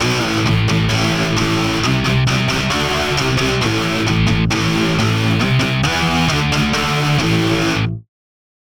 думаю, для таких звуков омнисфера вполне подойдет, вот просто взял 2 пресета из категории Distortion, больше ничего не рулил и не обрабатывал